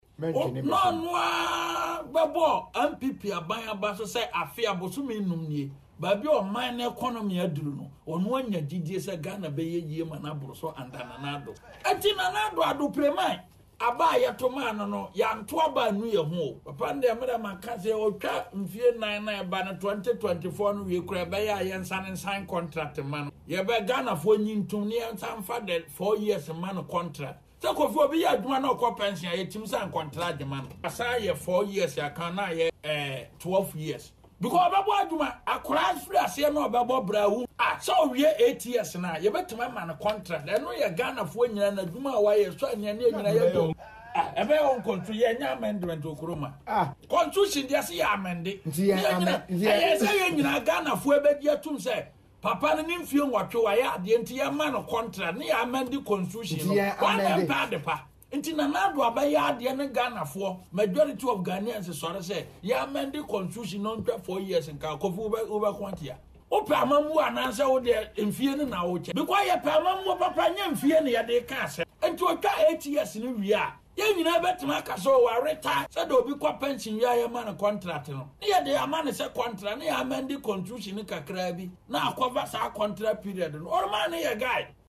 Speaking on Adom FM’s Morning Show